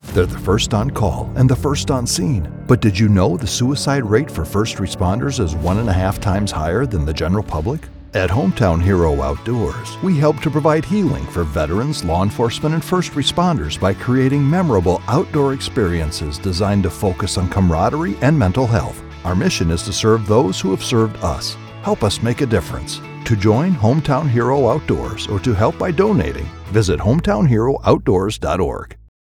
My voice has been described as rich, articulate, and engaging.
Local Radio Spot for Veteran Non-Profit
English - Midwestern U.S. English
I use a Sennheiser MKH 416 mic in a custom home studio.